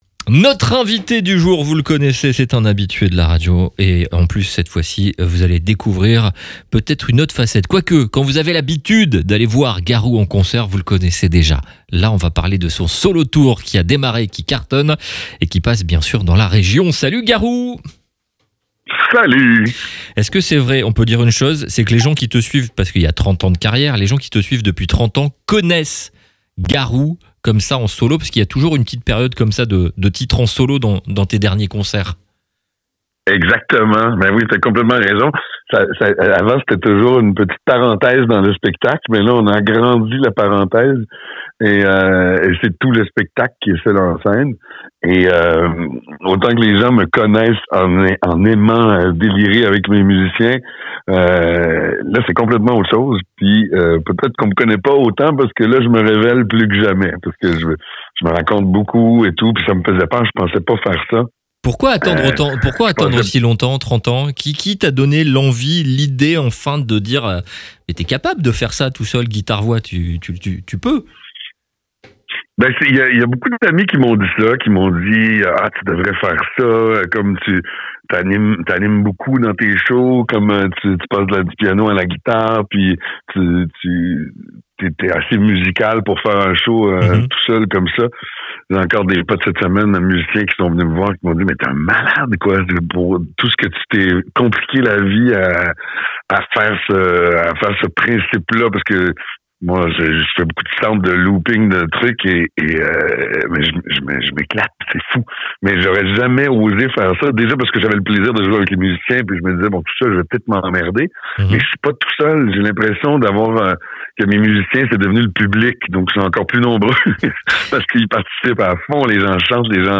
3. Les interviews exclusifs de RCB Radio